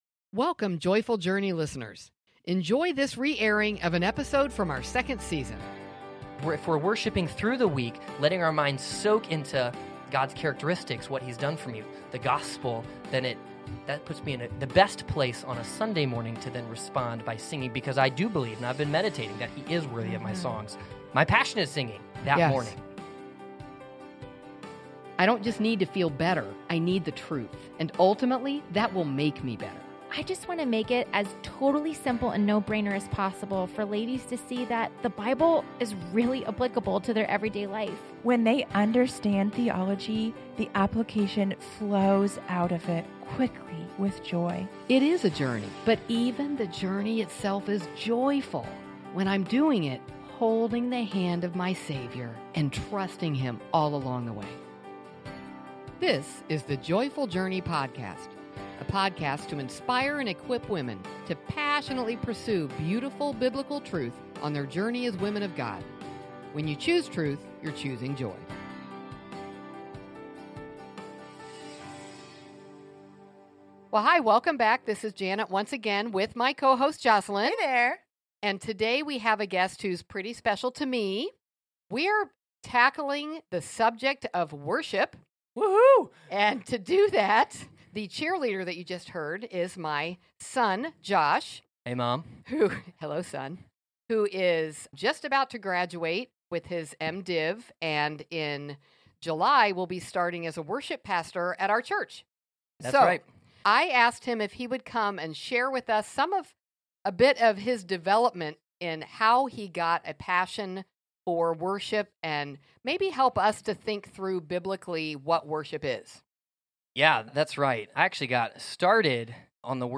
a thoughtful conversation about what worship truly is. Together, we explore the heart behind worship—why we do it, and what corporate worship means to God and to the people in our churches.